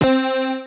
1_Lead.wav